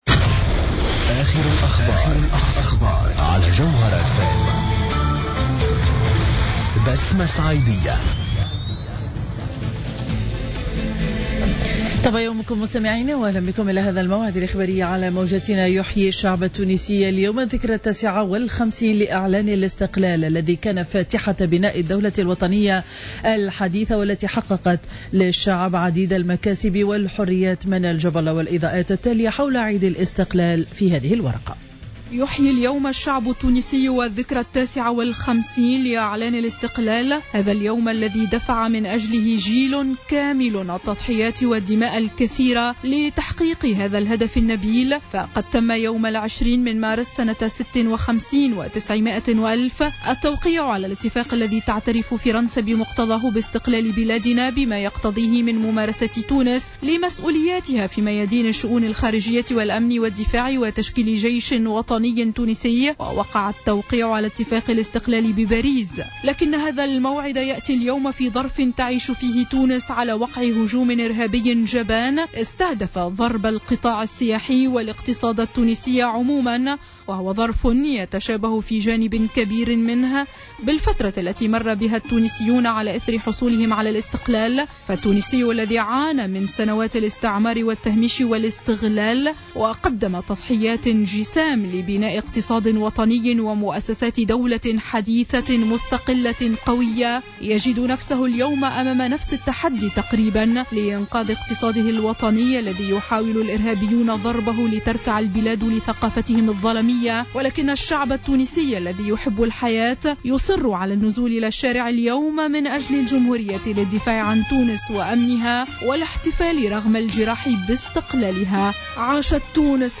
نشرة أخبار السابعة صباحا ليوم الجمعة 20 مارس 2015